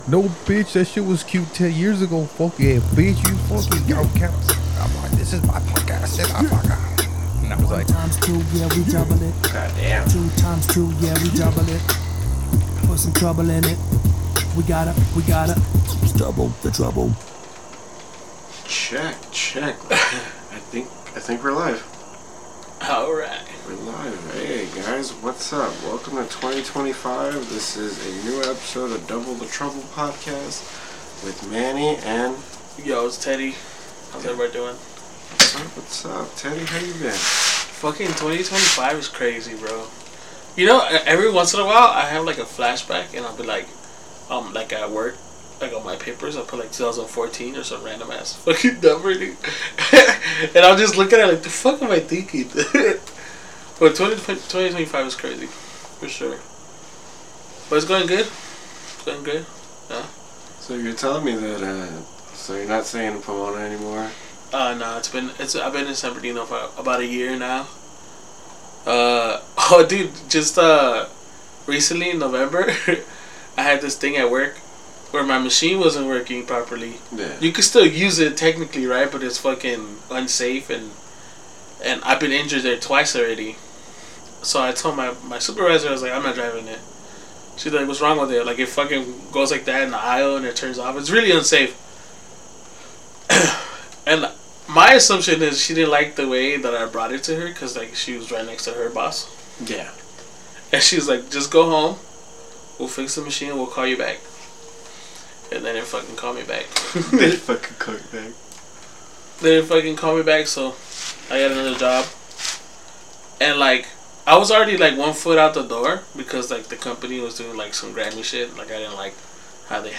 Two guys from So Cal talking about our weekly shenanigans. As well as interviewing local comedians, creators, and podcasters.